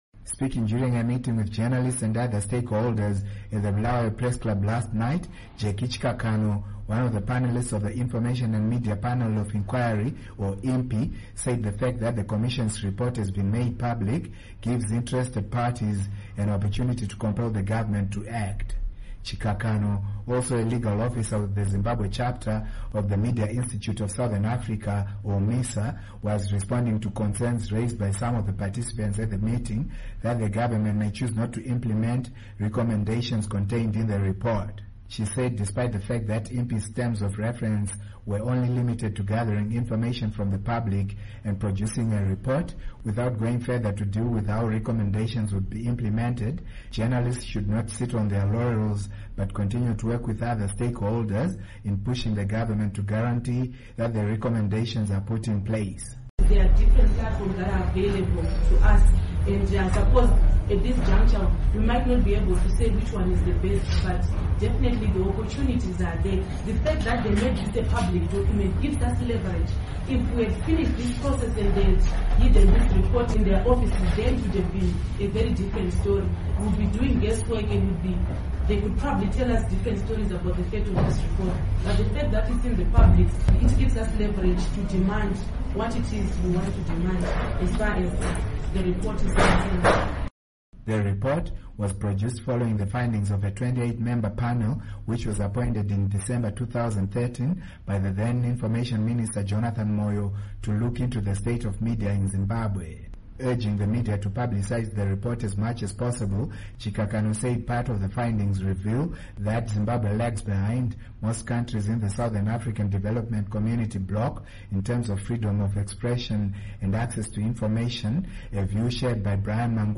Report on IMPI Findings Implementation